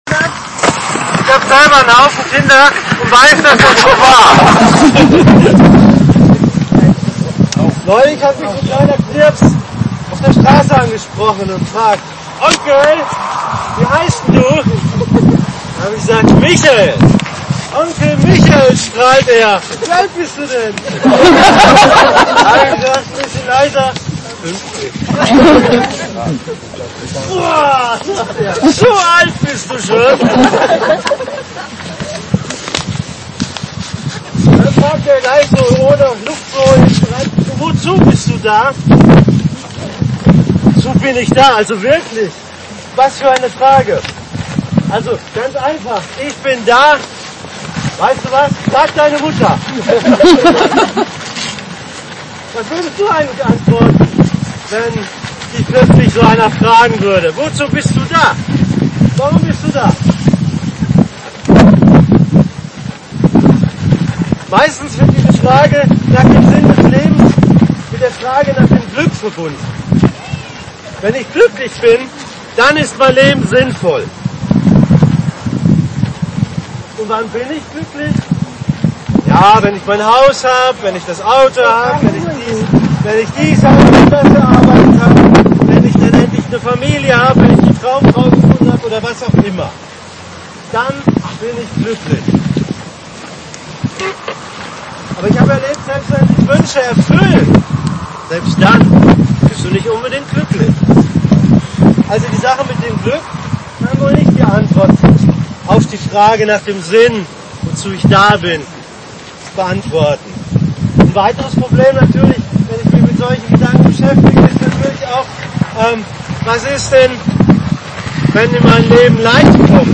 > Übersicht Predigten Der Sinn DEINES Lebens Predigt vom 11.
Die Aufnahme ist bedingt durch die Umst�nde (Au�enaufnahme w�hrend des Gemeindeausfluges an der See ohne technische Verst�rkung) leider von minderer Qualit�t. Wir bitten das zu entschuldigen.